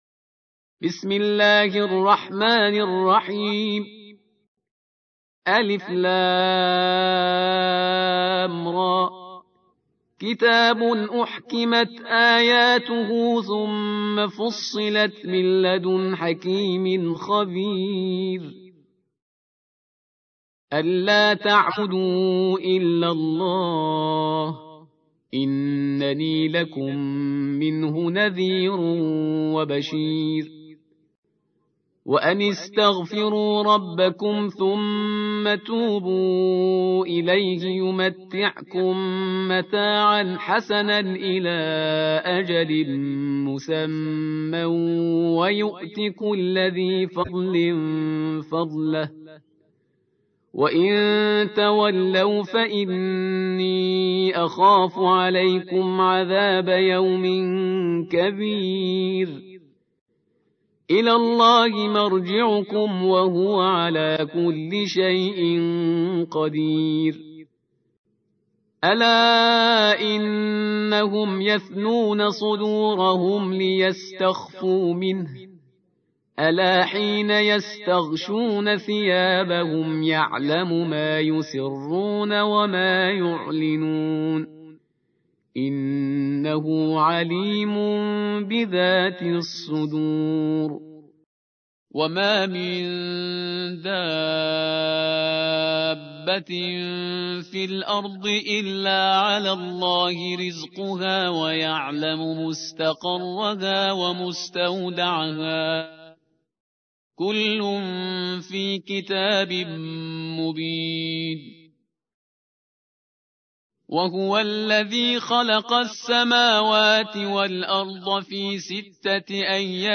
11. سورة هود / القارئ